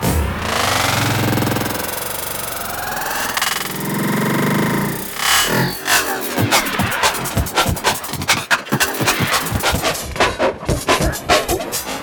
GrainDelay - A sub-sample accurate granular feedback Delay
In the feedback path is a OnePole lowpass filter with a dampen factor between 0 and 1 and a Highpass filter at a fixed frequency of 3 hz as a DC blocker.